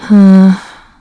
Isolet-Vox-Think.wav